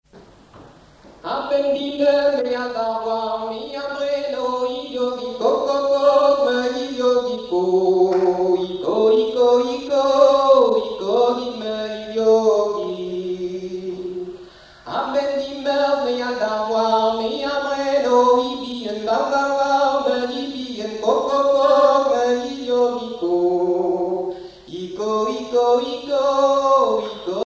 Chanson en breton
Témoignages et chansons
Pièce musicale inédite